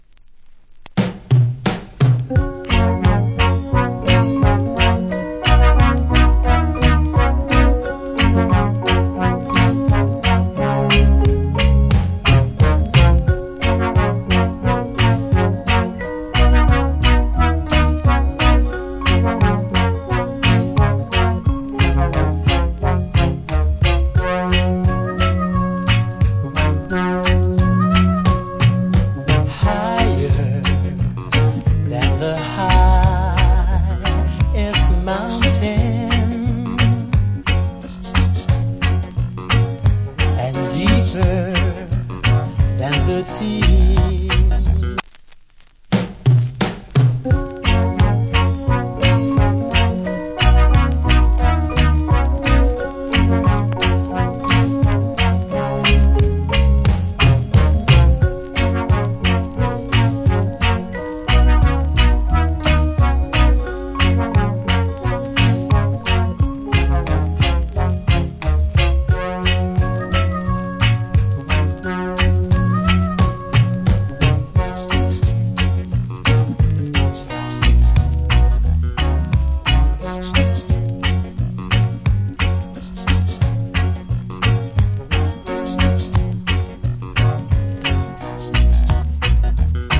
Old Hits Reggae Vocal.